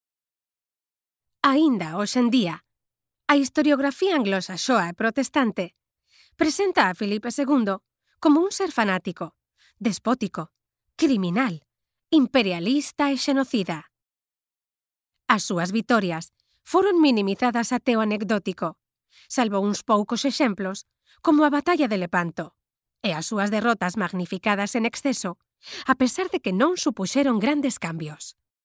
Galician female voice over